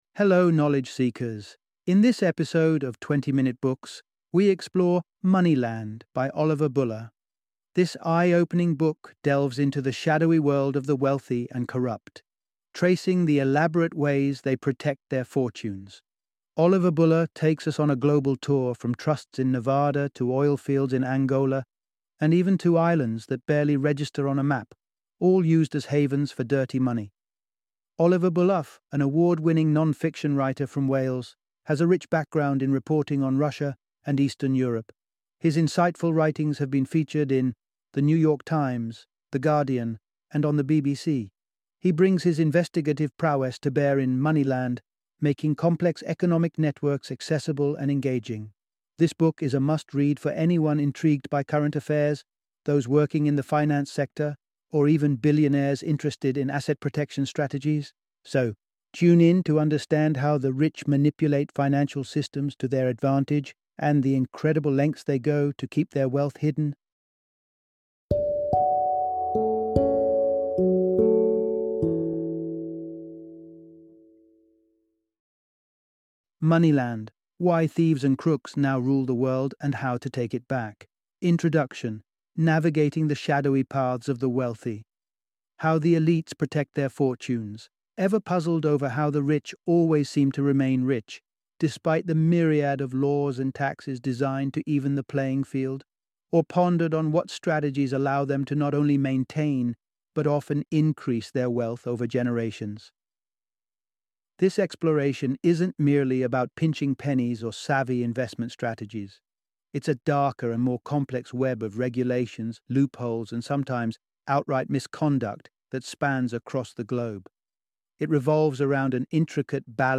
Moneyland - Audiobook Summary
Moneyland - Book Summary